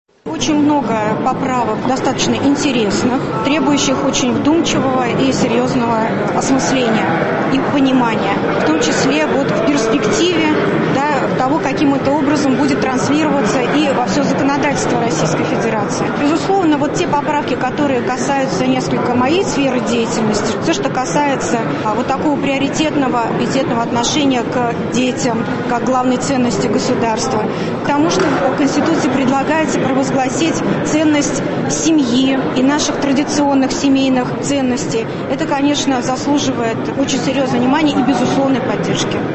Вот что рассказала корреспонденту ГТРК «Татарстан» уполномоченный по правам ребенка в республике Татарстан Гузель Удачина: